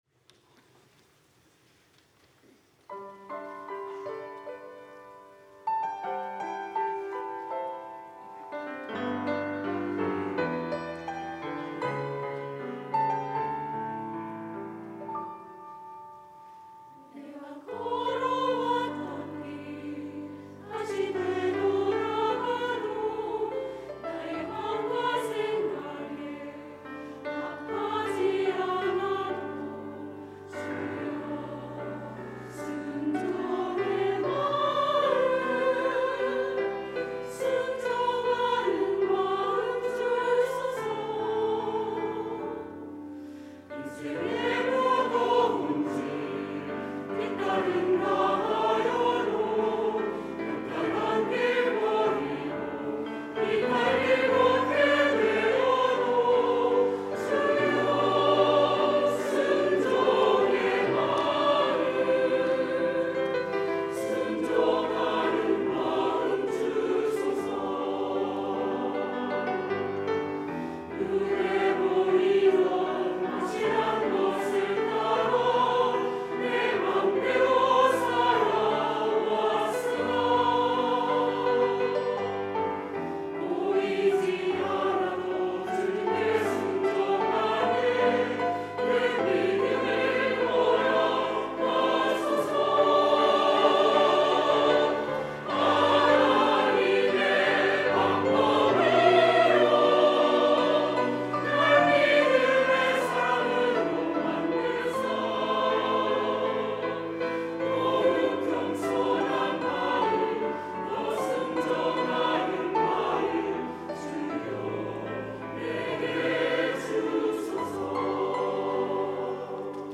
할렐루야(주일2부) - 순종하는 마음
찬양대